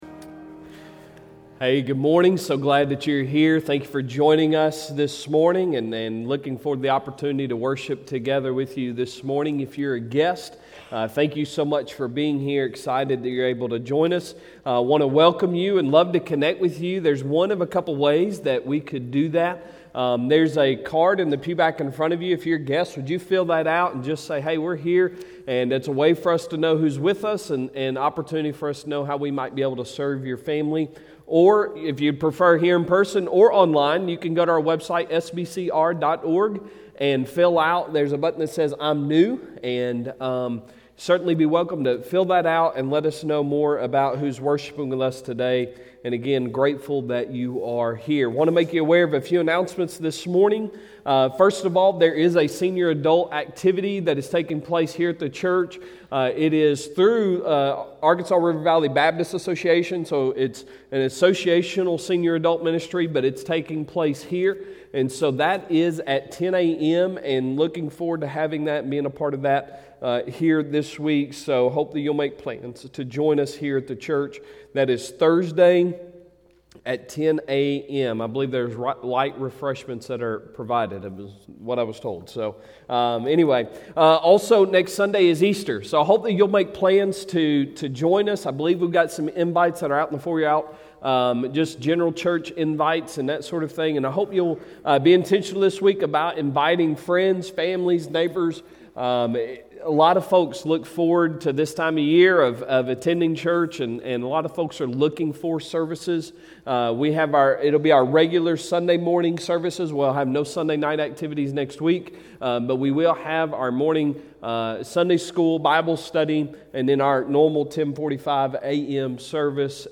Sunday Sermon April 10, 2022